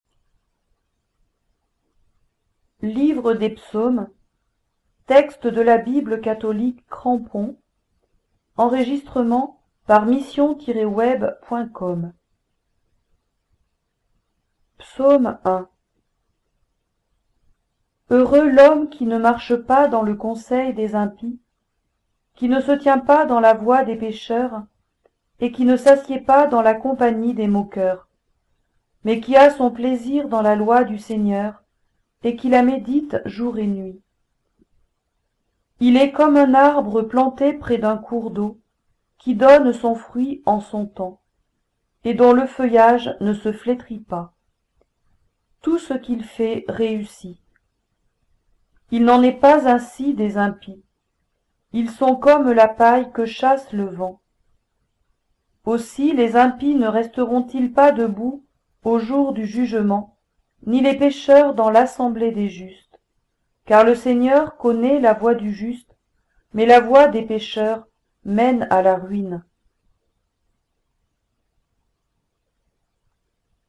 Ancien Testament - Livre des Psaumes
Il convient donc de les remplacer à la lecture par "le Seigneur" voir 'l'Éternel".
Cette précision apportée, le texte lu est aussi fidèle que possible à l'écrit.